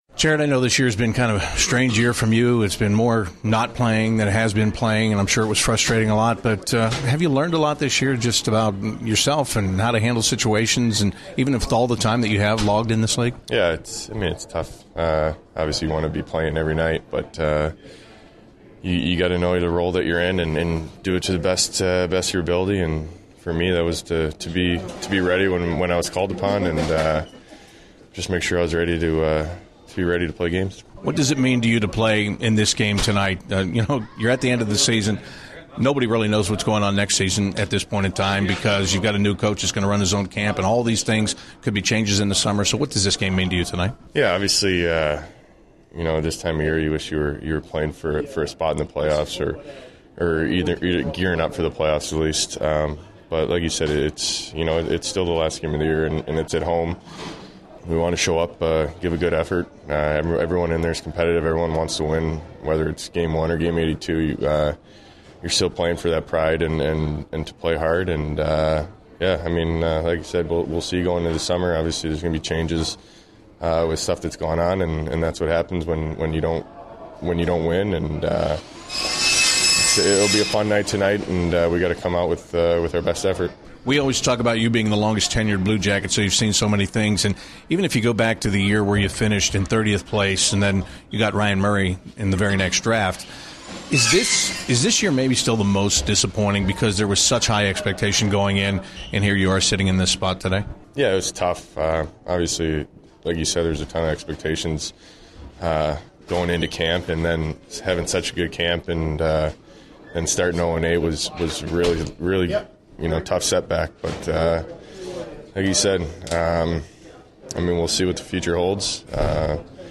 CBJ Interviews / Jared Boll Pre-Game 04/09/16